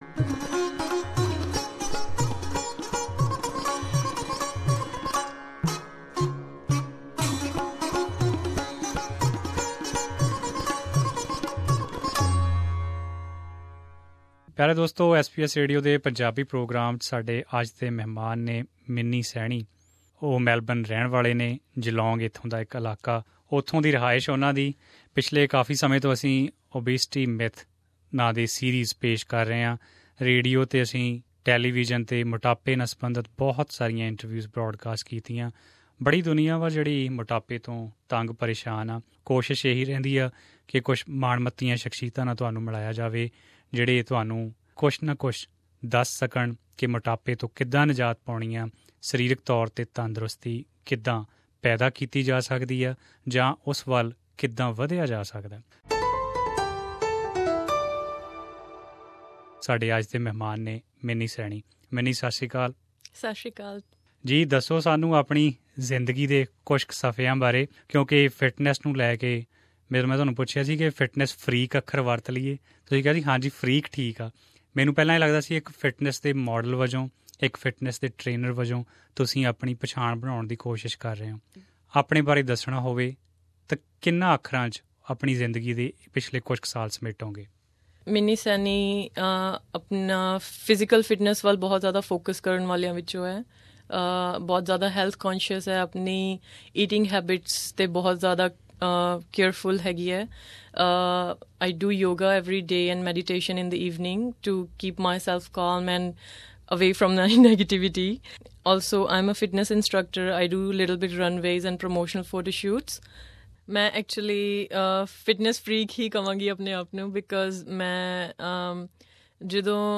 In an interview with SBS Punjabi